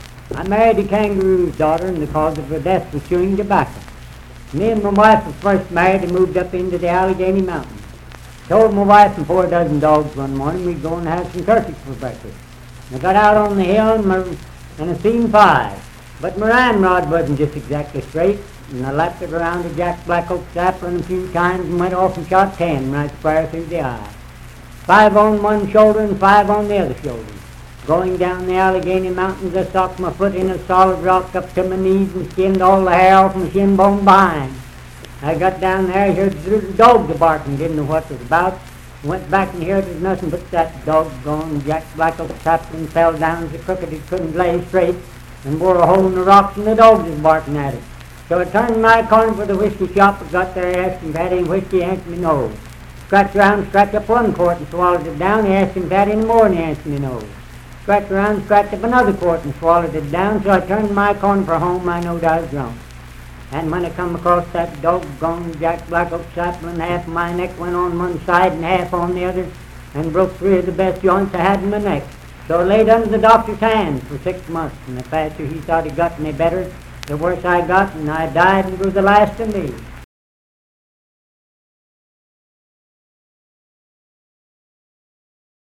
Unaccompanied vocal music and folktales
Voice (sung)
Parkersburg (W. Va.), Wood County (W. Va.)